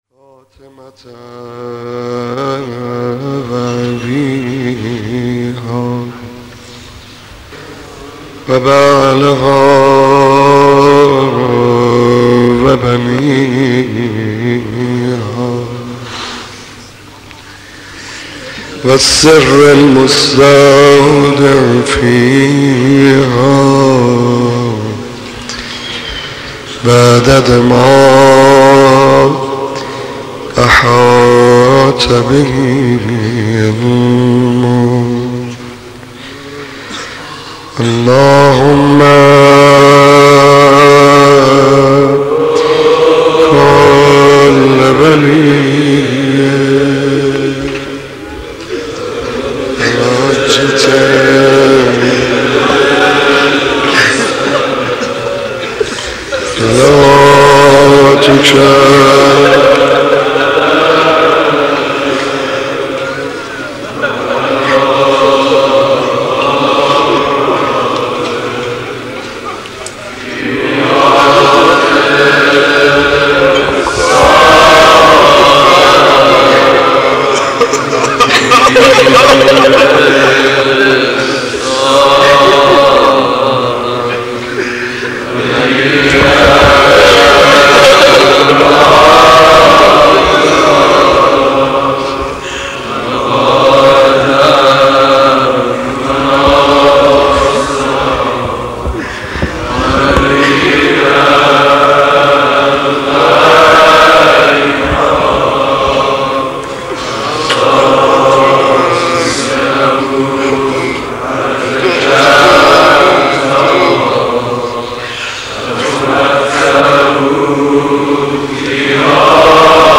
مداح
مناسبت : شب اول محرم
مداح : محمدرضا طاهری